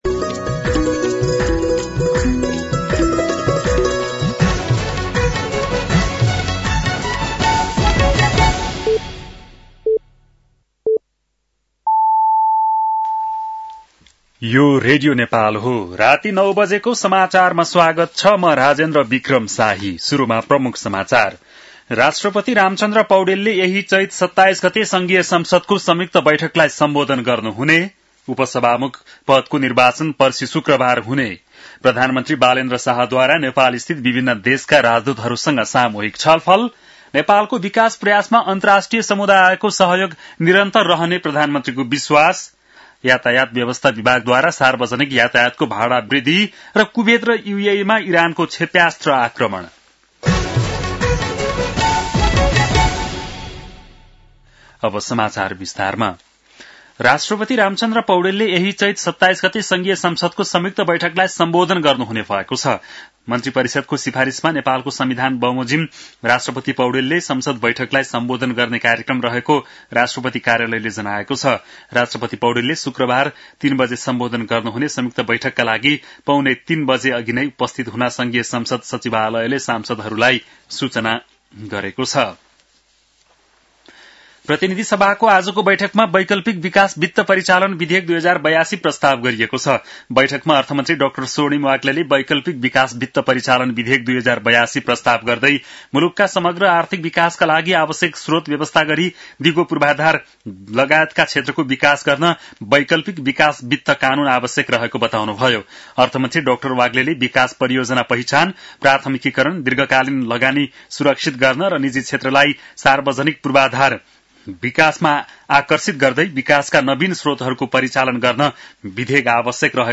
An online outlet of Nepal's national radio broadcaster
बेलुकी ९ बजेको नेपाली समाचार : २५ चैत , २०८२